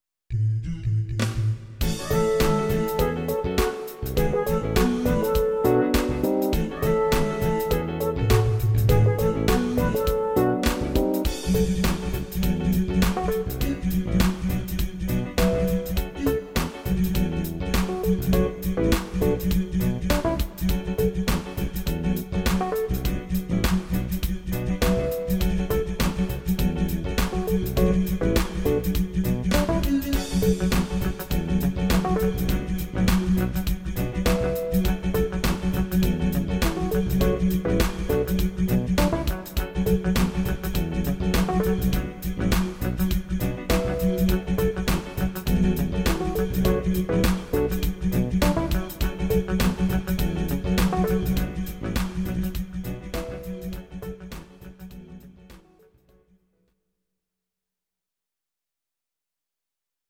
Audio Recordings based on Midi-files
German, 1990s